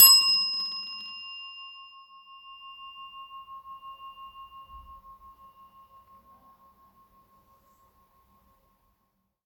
Desk Bell
bell chime desk ding ping ring service sound effect free sound royalty free Sound Effects